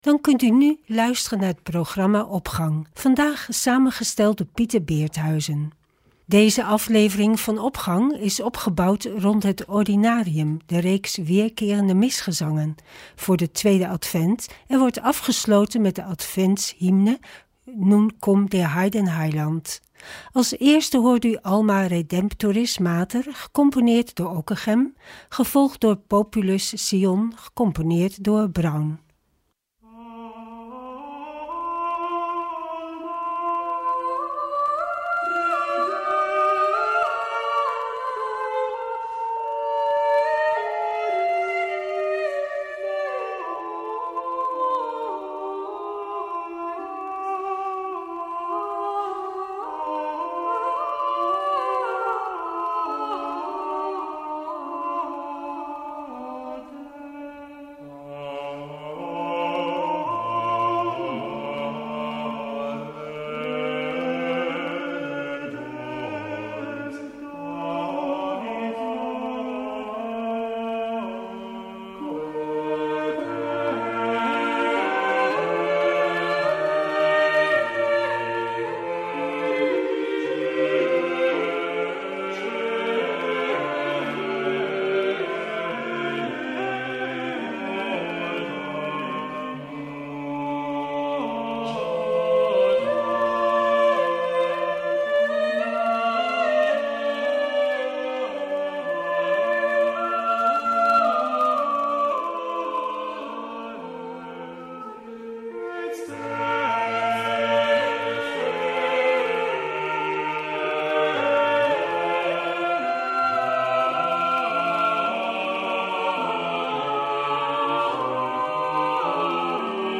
Opening van deze zondag met muziek, rechtstreeks vanuit onze studio.
De Advent is de aanloopperiode naar Kerst, een periode van voorbereiding, boete en inkeer, en gebed, gesymboliseerd door de liturgische kleur paars. Populus Sion is een gregoriaans introïtusgezang voor de mis van de tweede zondag van de Advent, en tevens de naam voor deze zondag van de Advent.